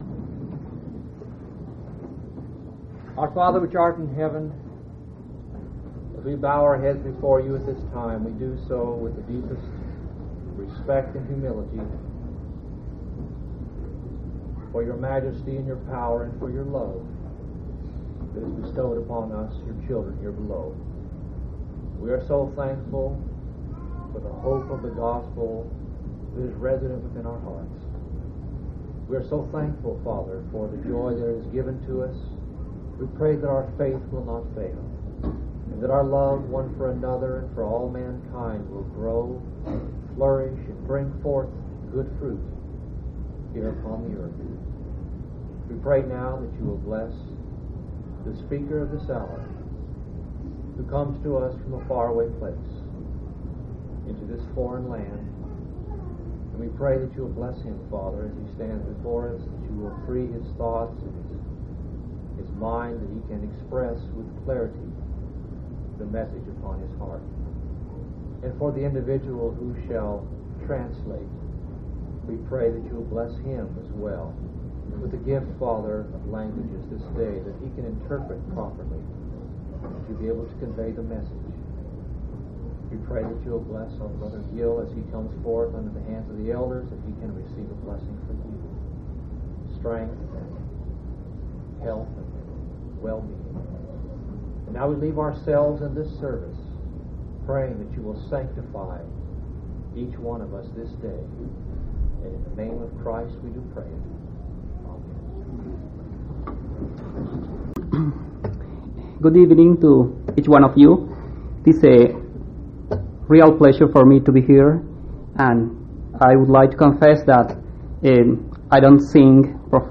8/13/1994 Location: Missouri Reunion Event: Missouri Reunion